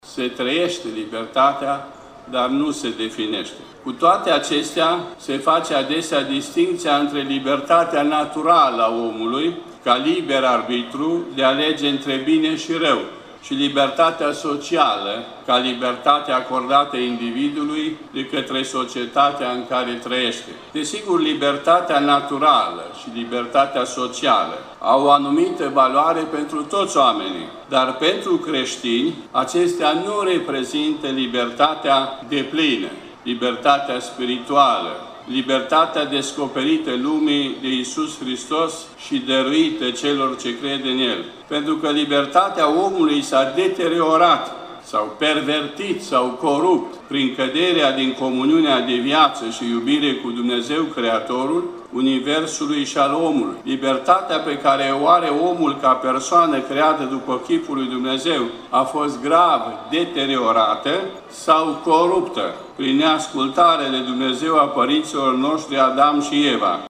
Peste şase mii de adolescenţi din 24 de ţări din Europa, Africa, America şi Orientul Apropiat participă, la Iaşi, timp de patru zile, la cea de-a patra ediţie a Întâlnirii Internaţionale a Tinerilor Ortodocşi.
La deschiderea evenimentului a fost prezent şi Patriarhul Bisericii Ortodoxe Române, Preafericitul Daniel, care a rostit un cuvînt de învăţătură.